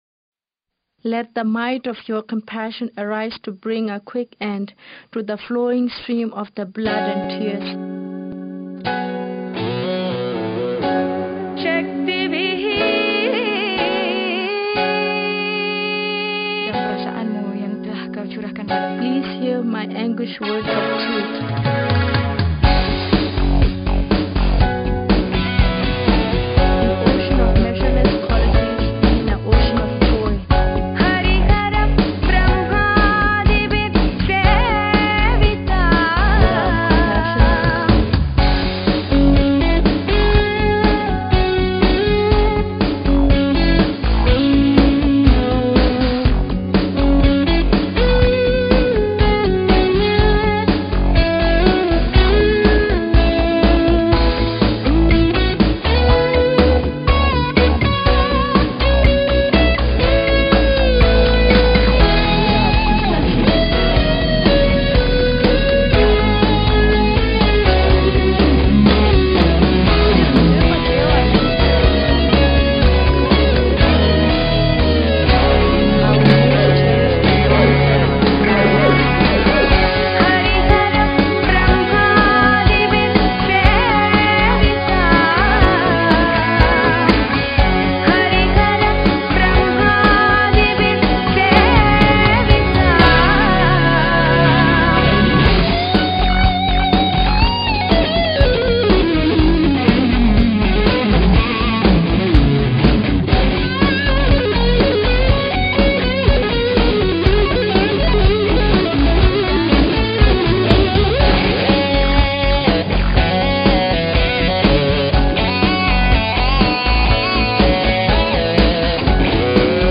0269-吉他名曲血与泪.mp3